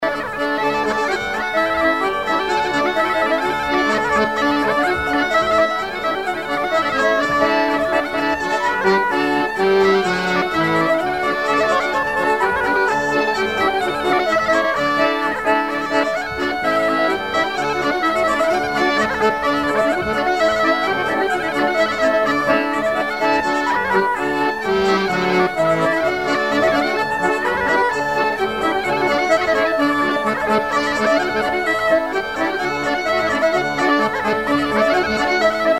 danse : branle : courante, maraîchine
bal traditionnel à la Minoterie, à Luçon
Pièce musicale inédite